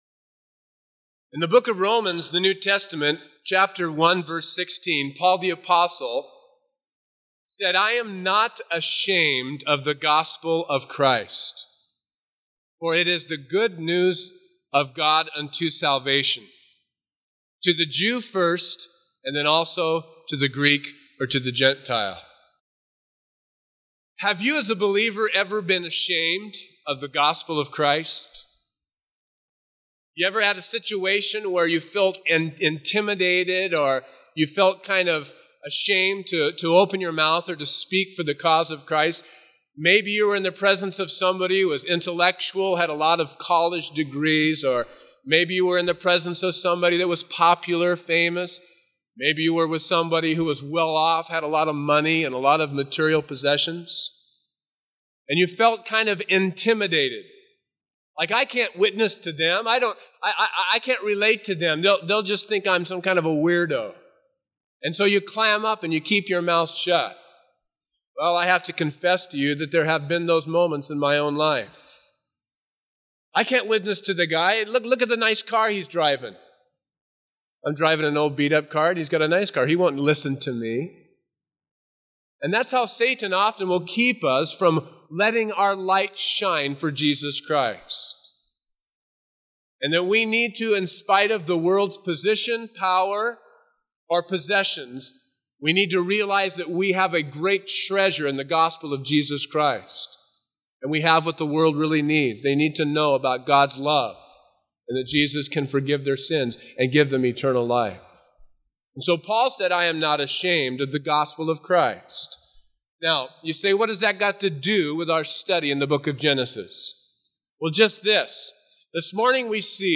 A verse-by-verse expository sermon through Genesis 47